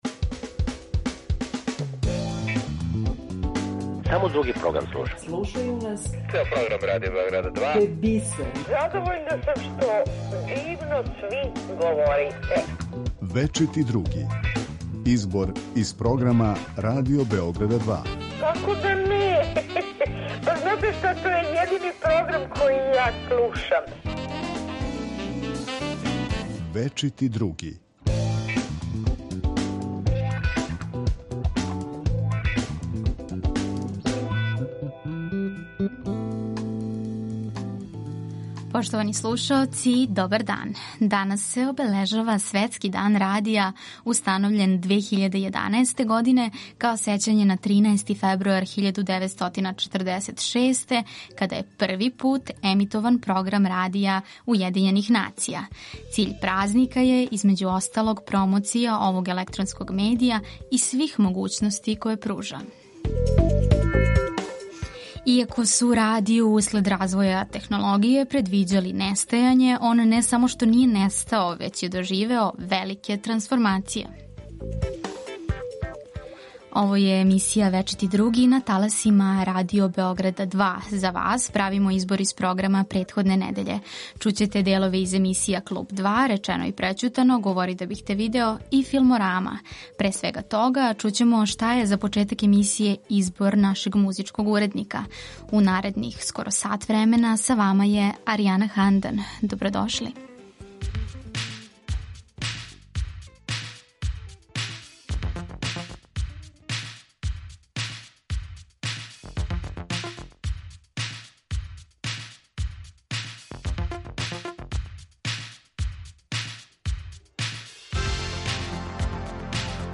Гост нашег програма био је Светислав Басара, који је говорио о недавно добијеној Ниновој награди. Од режисера Предрага Гаге Антонијевића слушаћемо о моћи филма „Дара из Јасеновца", стереотипима и питањима која је покренуо, а глумица Анђелка Прпић говориће о новом трилеру у коме игра главну улогу.